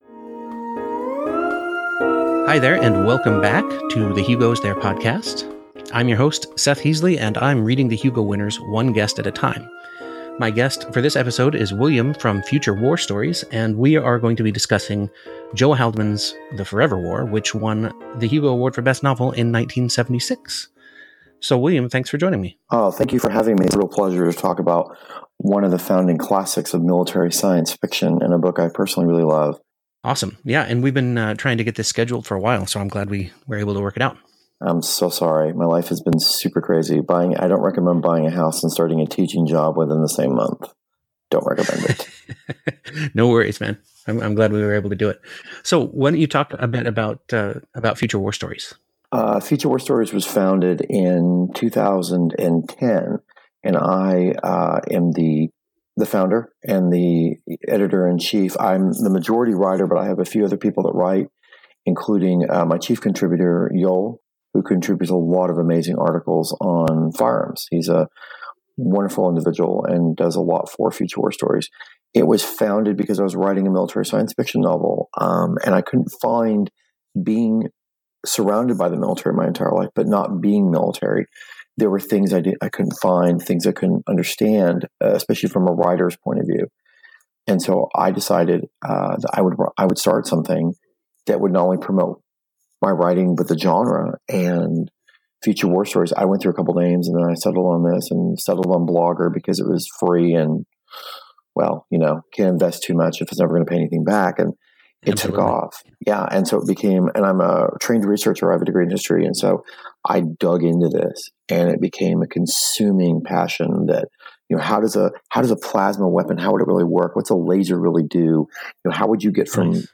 Note: We had some weird audio artifact…